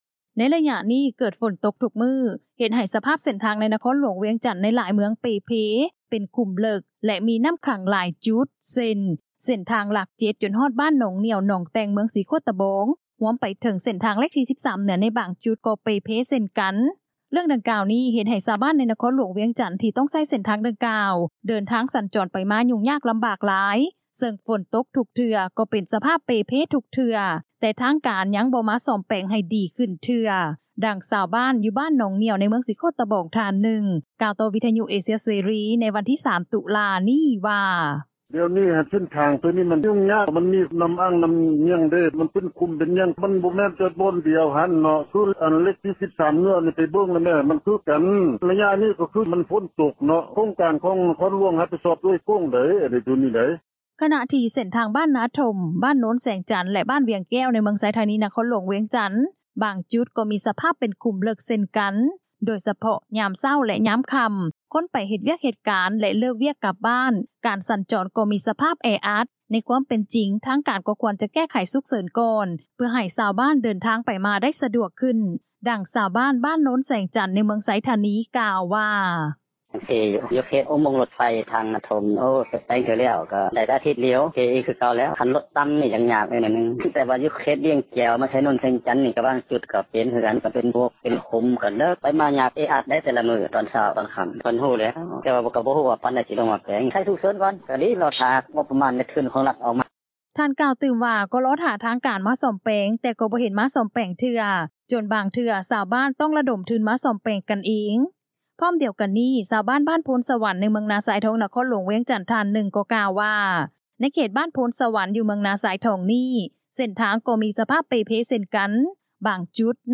ດັ່ງຊາວບ້ານ ບ້ານໂນນແສງຈັນ ໃນເມືອງໄຊທານີ ກ່າວວ່າ:
ດັ່ງຄົນຂັບຣົຖບັນທຸກ ທ່ານນຶ່ງກ່າວວ່າ: